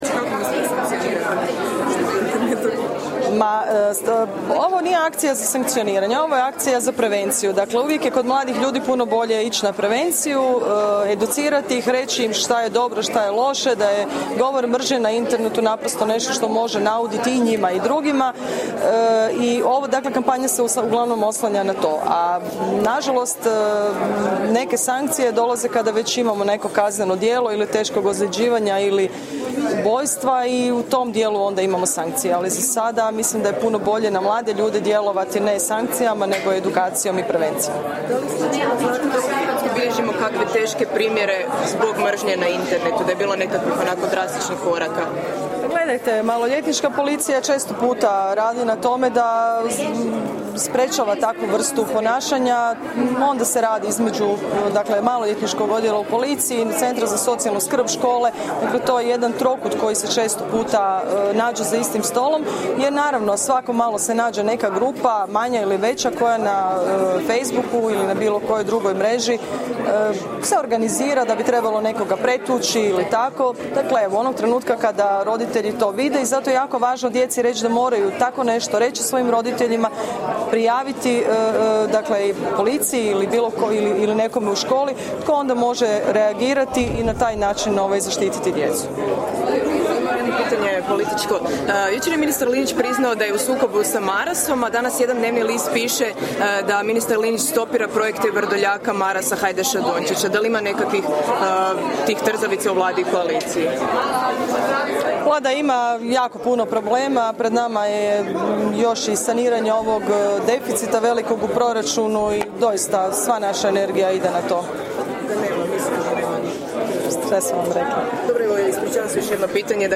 Izjava potpredsjednice Vlade i i ministrice socijalne politike i mladih Milanke Opačić